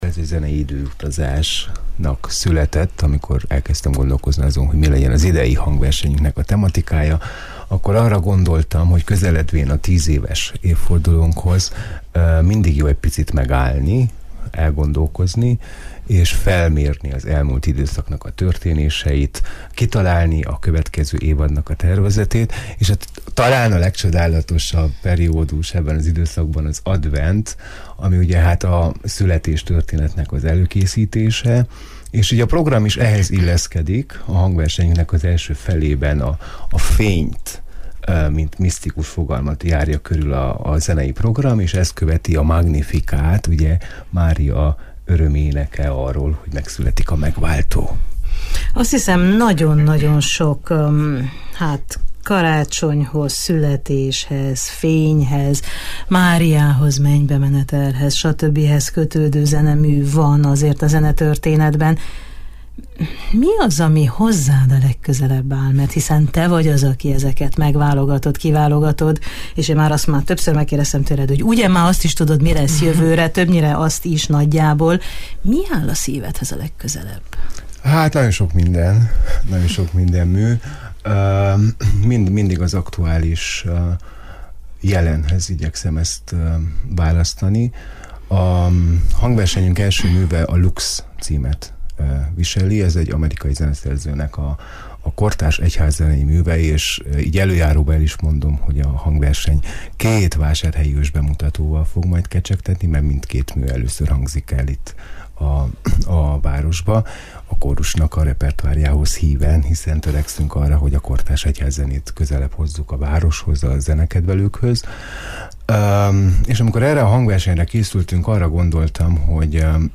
Vendégünk volt az Átjáróban a kórus két tagja